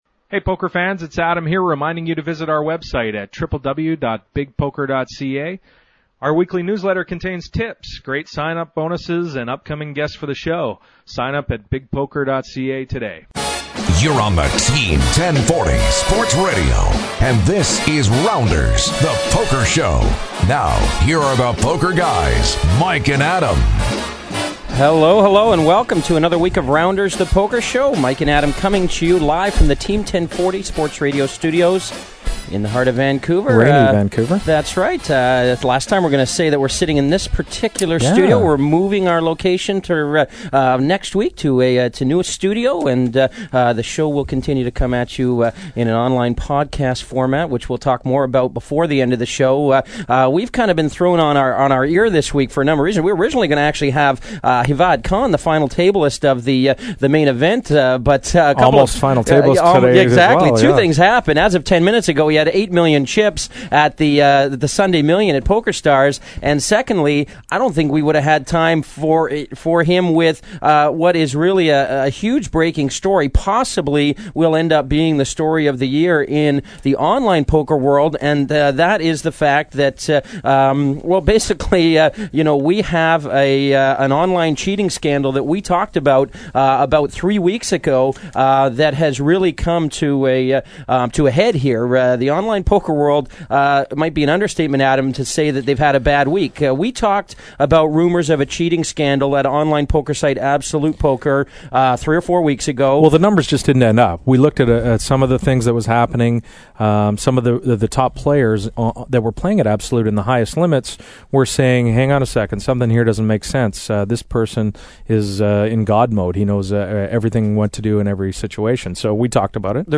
Airtime: at least an hour's worth on the radio (click me for a really long interview) , and probably another three or four hours' worth of phone calls with the media, not counting emails and back and forth with AP management.